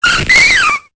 Cri de Joliflor dans Pokémon Épée et Bouclier.